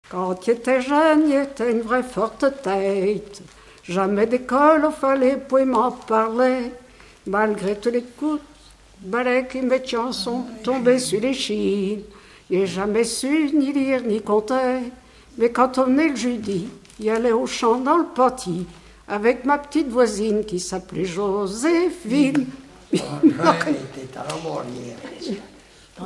Mémoires et Patrimoines vivants - RaddO est une base de données d'archives iconographiques et sonores.
assemblage de deux chansons patoisantes
Pièce musicale inédite